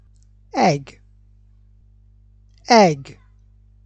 (eh-g)
eh-g.mp3